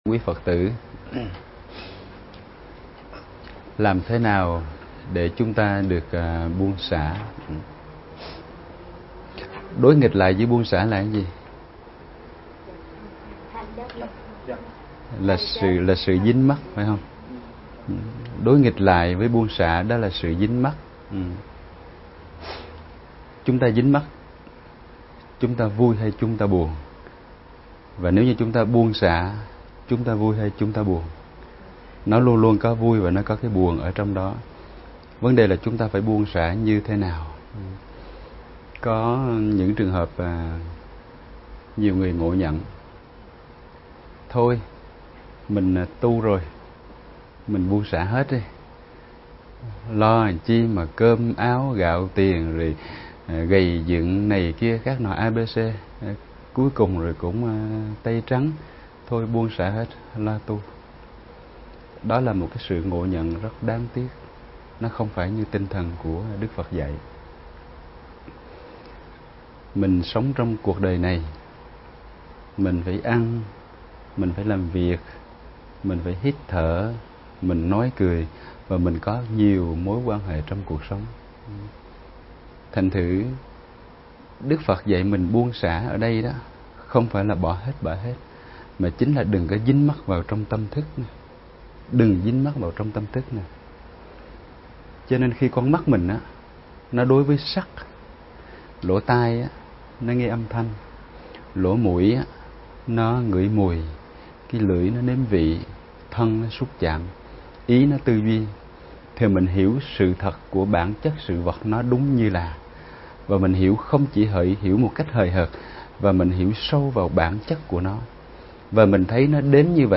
Vấn đáp: Phương pháp để tâm được buông xả